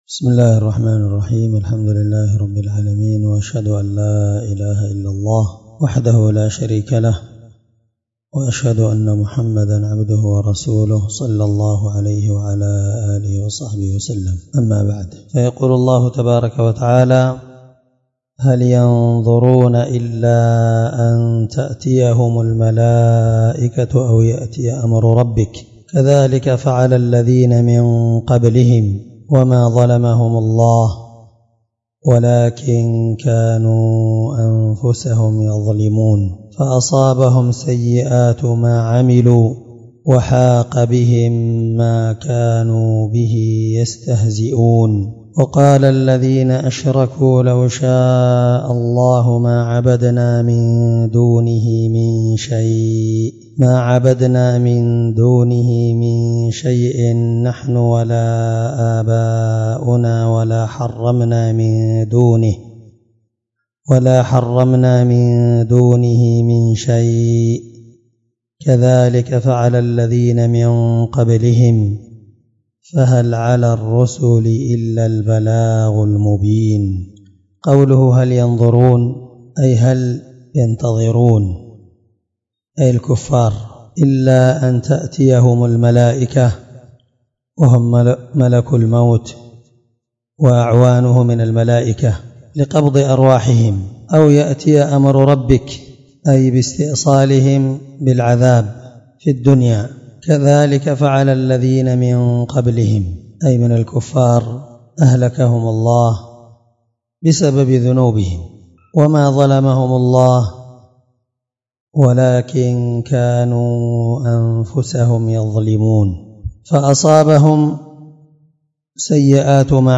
الدرس 8 تفسير آية (33-35) من سورة النحل